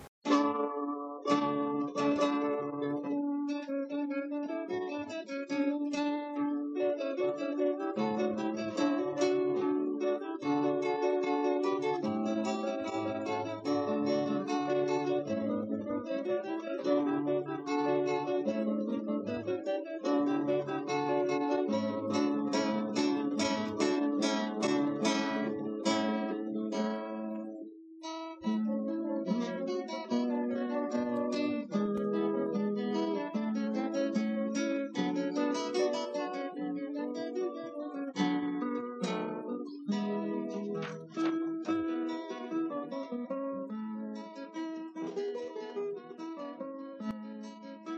Gitarrenmusik
Mit ihm habe ich auch einige Duette gespielt. Hier als Beispiel die Sonate von Scheidler: